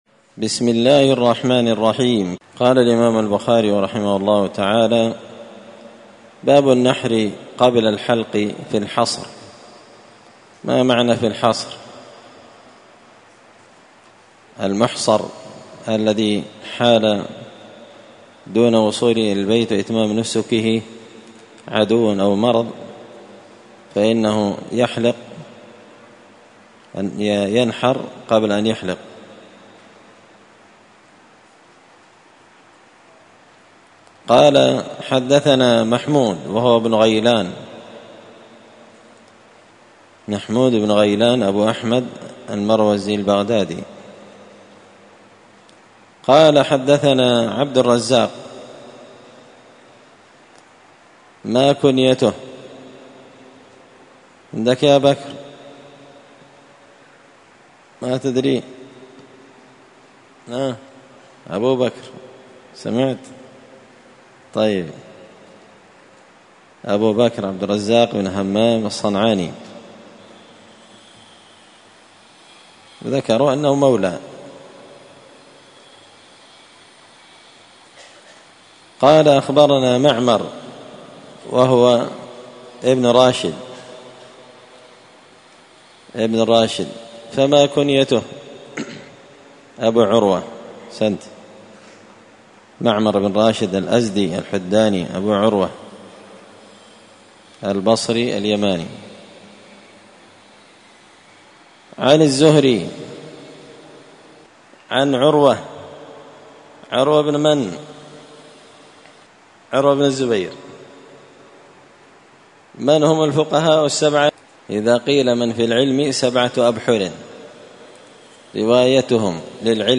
كتاب المحصر من شرح صحيح البخاري- الدرس 4 باب النحر قبل الحلق في الحصر
مسجد الفرقان قشن المهرة اليمن